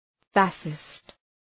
Προφορά
{‘fæʃıst}